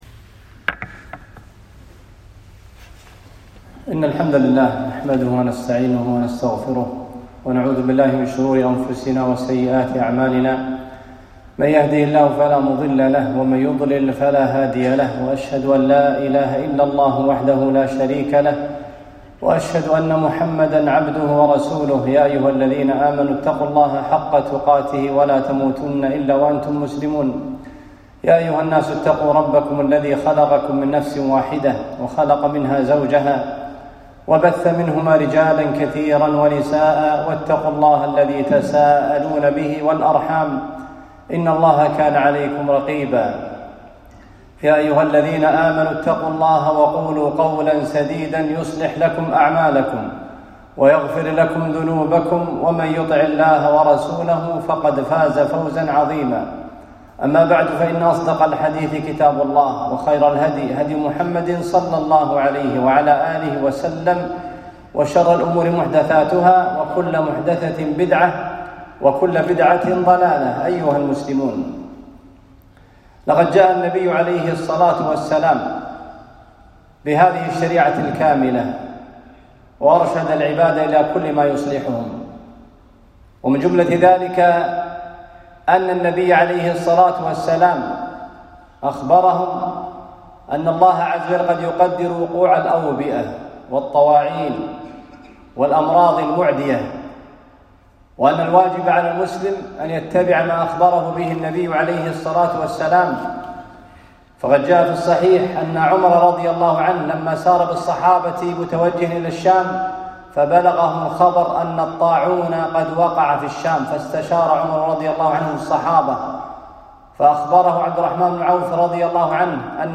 خطبة - تنبيهات نبوية عند وقوع الاؤبئة والطواعين